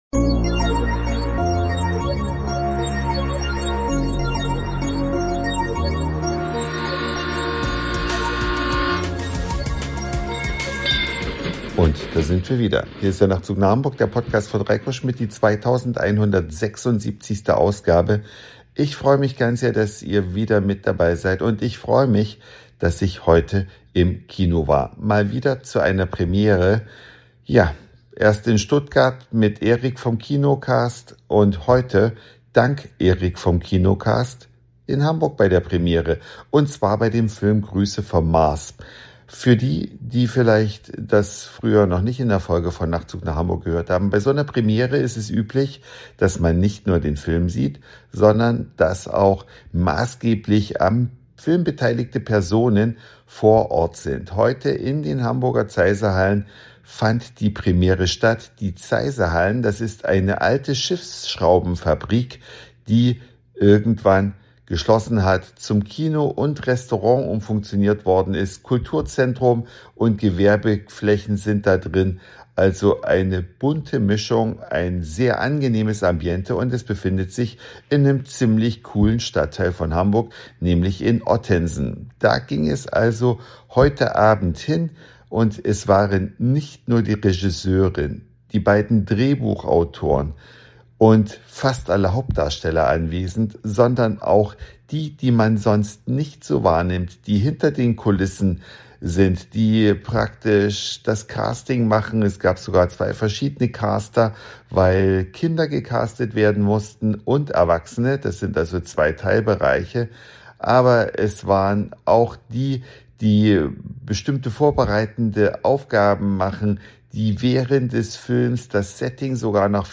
Eine Reise durch die Vielfalt aus Satire, Informationen, Soundseeing und Audioblog.
Film Premiere im Zeise Kino, Zeit für ein Interview mit der